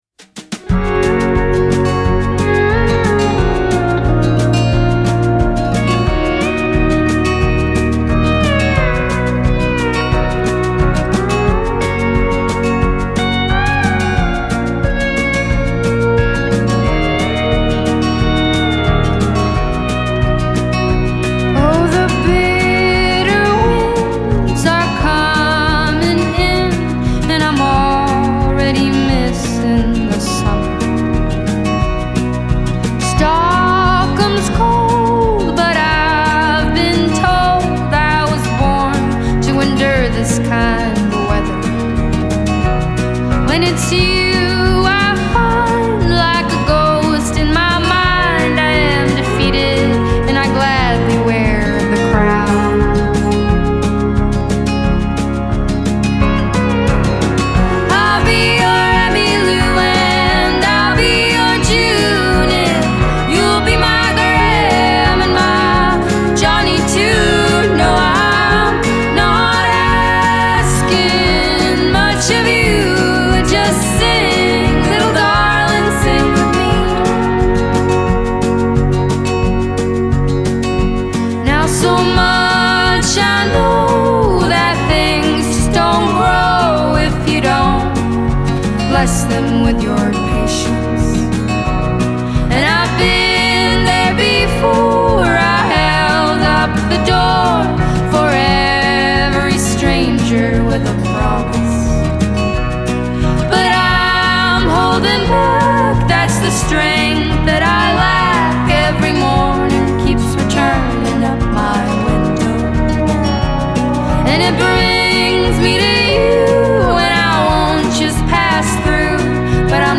Swedish sister duo